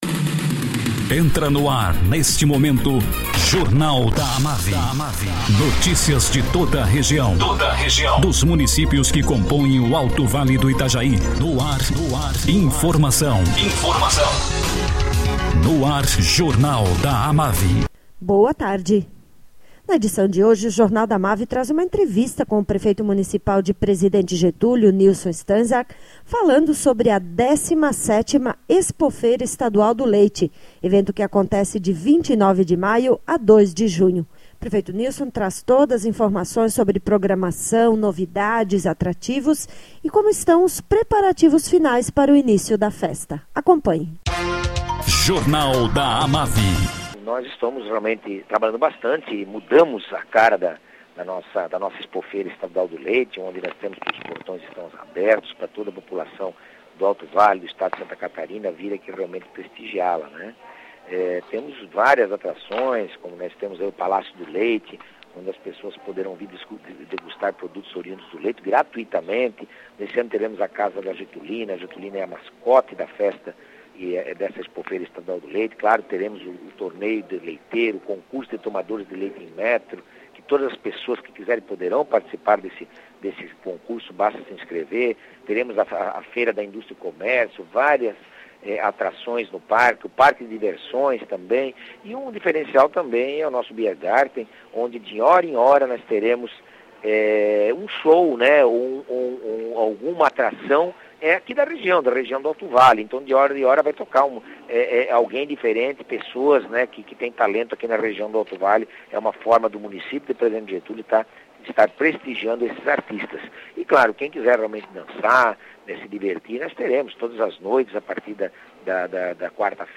Prefeito municipal de presidente Getúlio, Nilson Francisco Stainsack, fala sobre as atrações da 17ª Expofeira Estadual do leite que acontece de 29 de maio a 2 de junho.